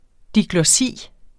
Udtale [ diglʌˈsiˀ ]